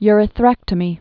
(yrĭ-thrĕktə-mē)